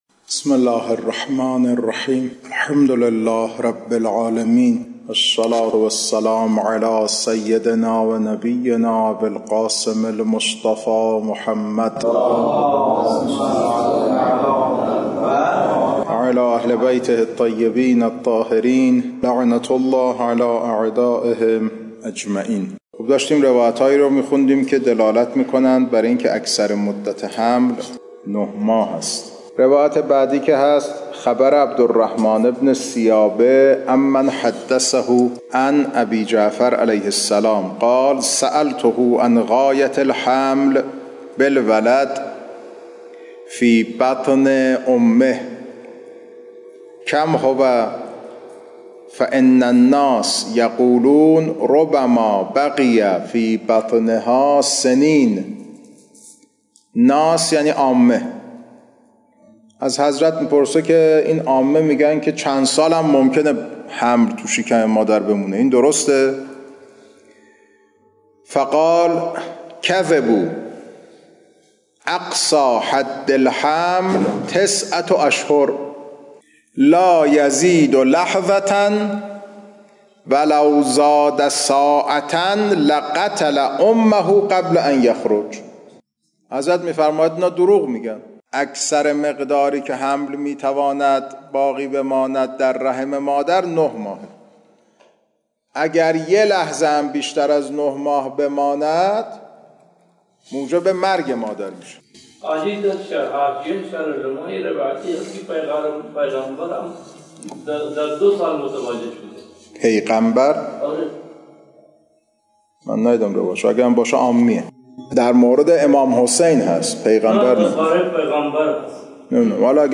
خارج فقه، بحث نکاح